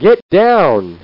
Get Down! Sound Effect
Download a high-quality get down! sound effect.